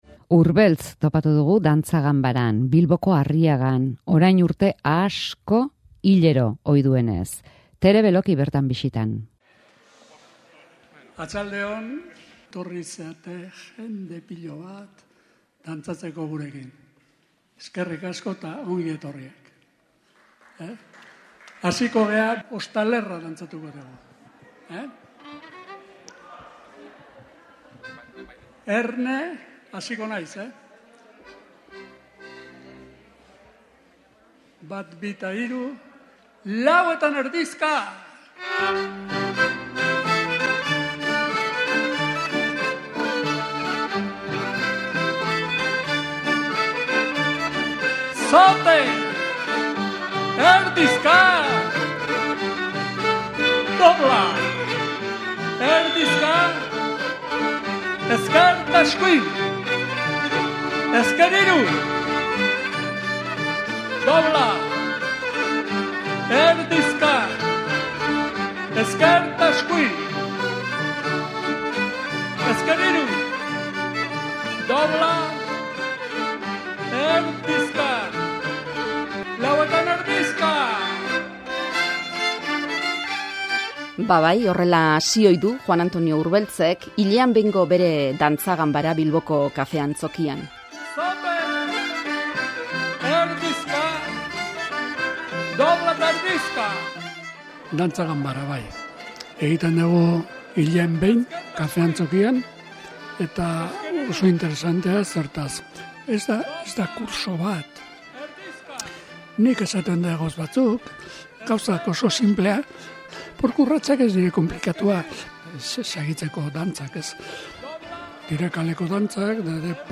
elkarrizketak
tartean doinu dezente entzuteko aukera dago.